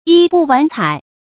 衣不完采 yī bù wán cǎi
衣不完采发音